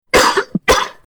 Coughing
coughing.mp3